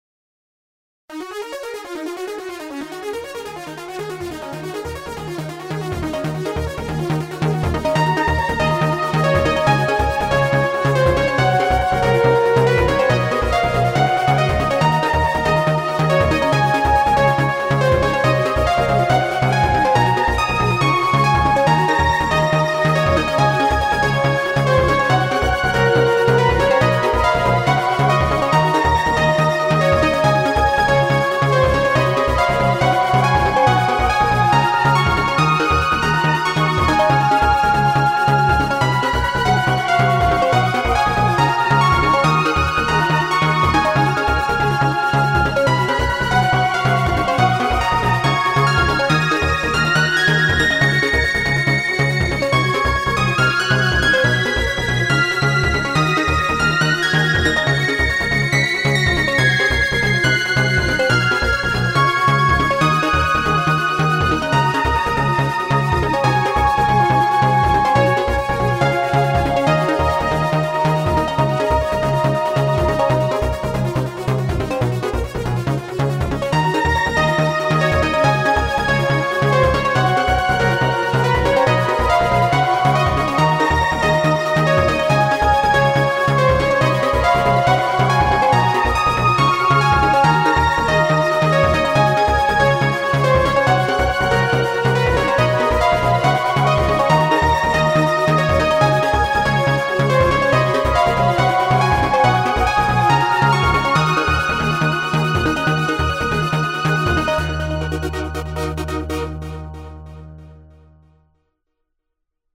BGM
EDMファンタジー激しい